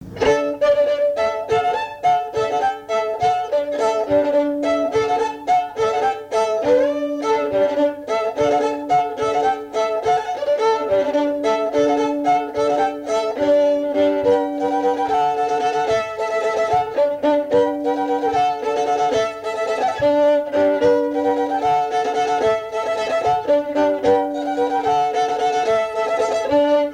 Mémoires et Patrimoines vivants - RaddO est une base de données d'archives iconographiques et sonores.
danse : bourree
Pièce musicale inédite